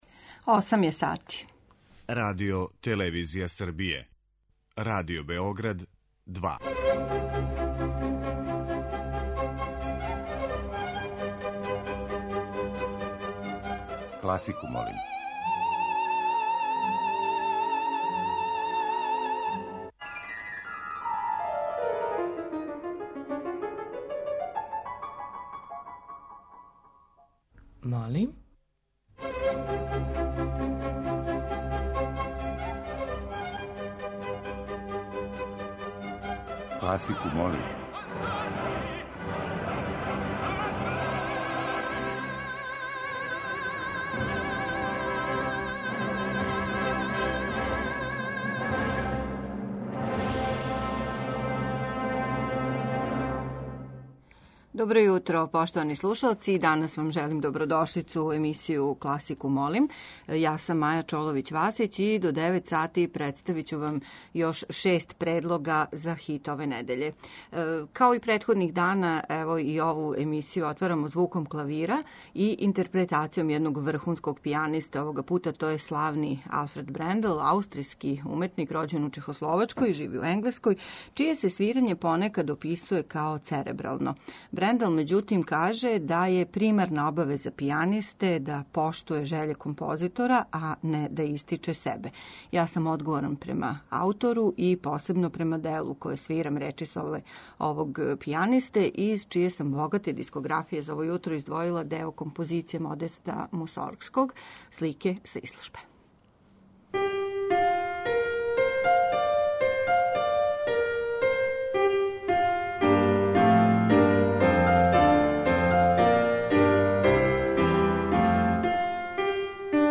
Као и обично, листу предлога за овонедељни хит класичне музике чине, како нови, тако и архивски снимци забележени на дискографским издањима и концертима реномираних домаћих и страних уметника. У оквиру теме циклуса чућете како су композитори попут Бацинија, Грига, Попера и Листа дочарали свет патуљака.
Уживо вођена емисија, окренута широком кругу љубитеља музике, разноврсног је садржаја, који се огледа у подједнакој заступљености свих музичких стилова, епоха и жанрова.